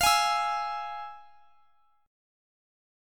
Listen to FmM7 strummed